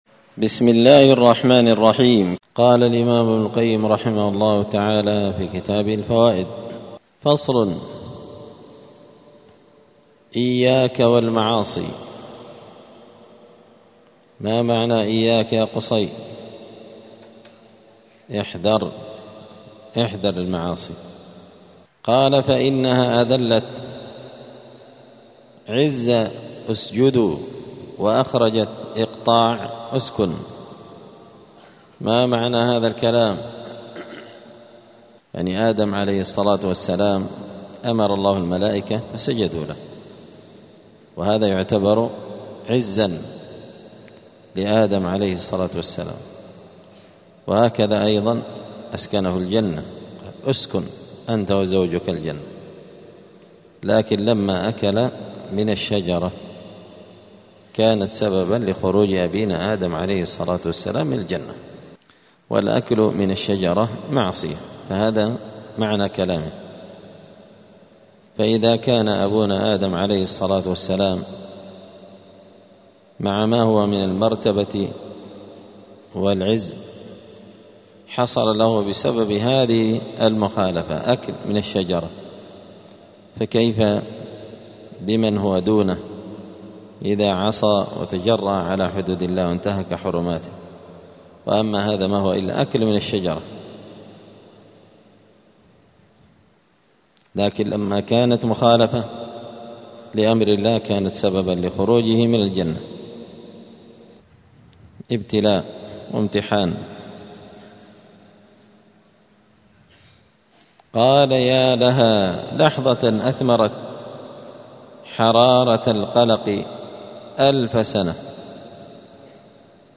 *الدرس السادس عشر (16) (فصل: إياك والمعاصي)*
دار الحديث السلفية بمسجد الفرقان قشن المهرة اليمن